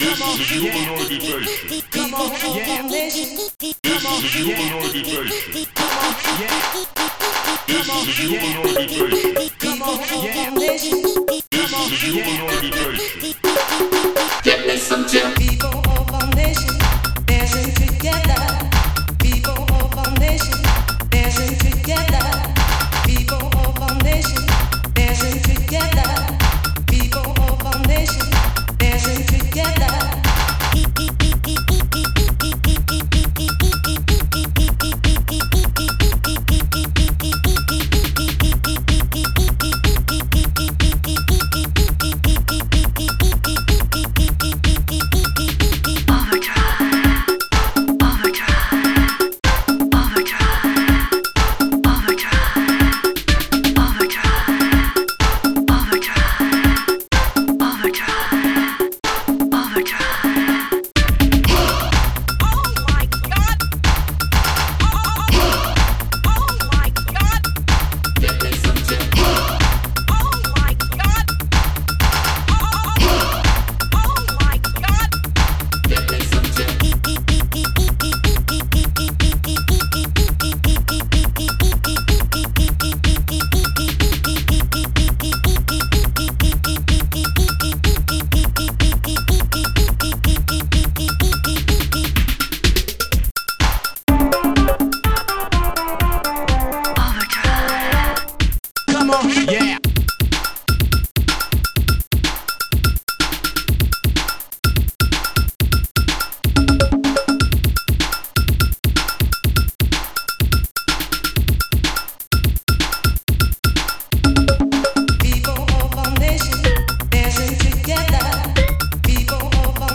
Protracker Module  |  1990-11-10  |  202KB  |  2 channels  |  44,100 sample rate  |  3 minutes, 19 seconds
Protracker and family